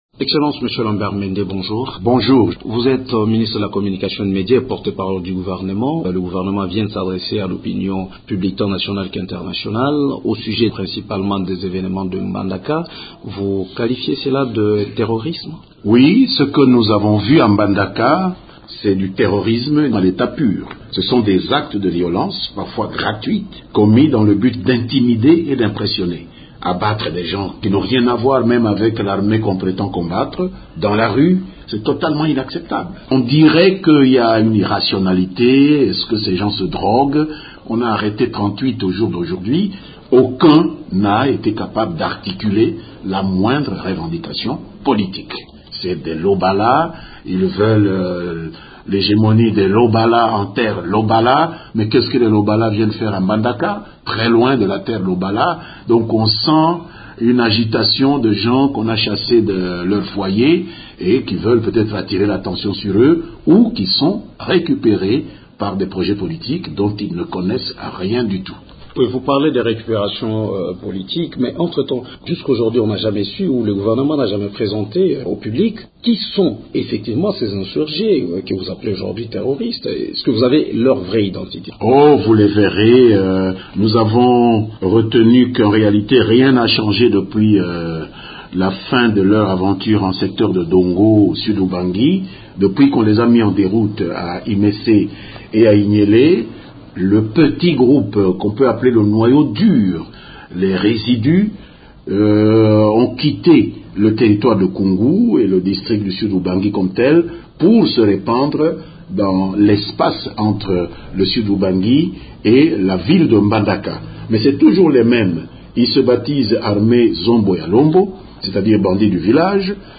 Lambert Mende est notre invité du jour.